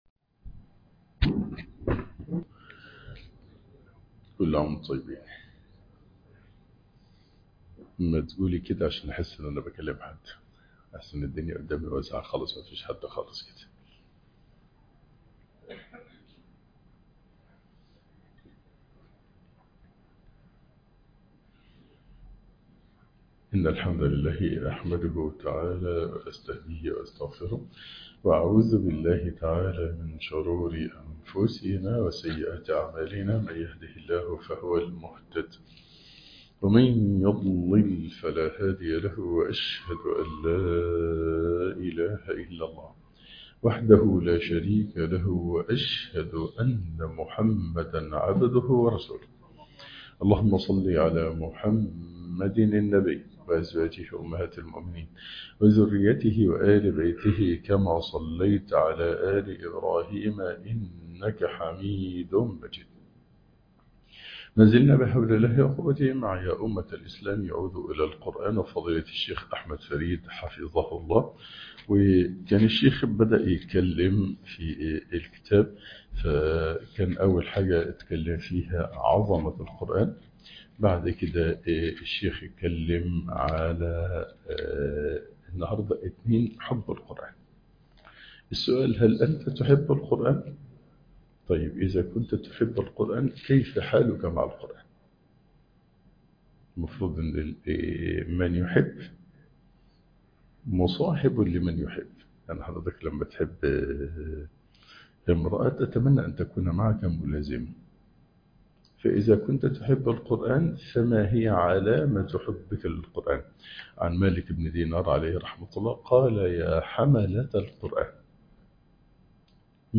ياأمة الإسلام عودوا إلى القرأن الدرس الخامس